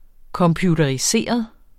Udtale [ kʌmpjudʌɐ̯iˈseˀʌð ]